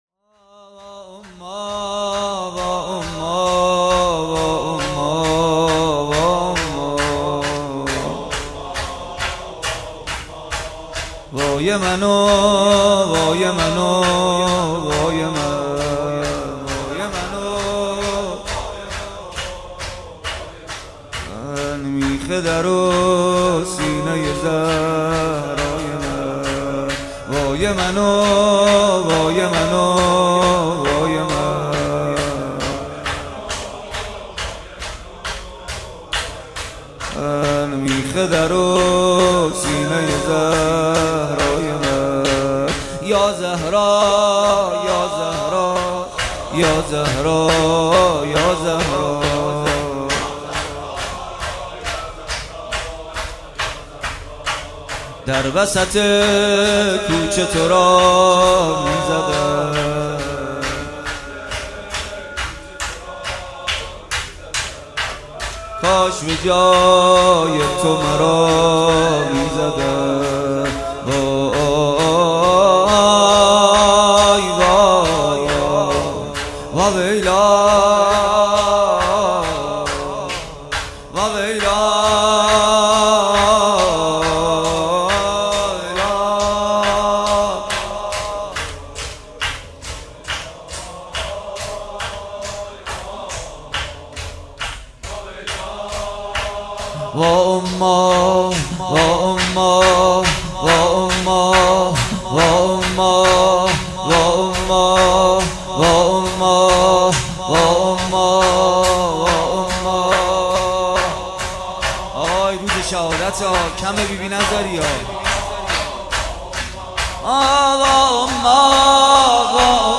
ظهر 11 بهمن 96 - ریحانه النبی - شور - وا اماه